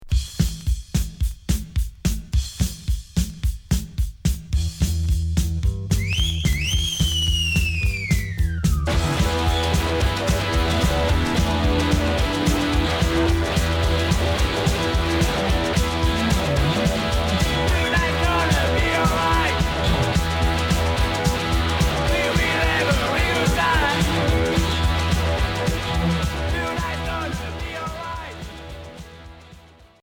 Garage Unique 45t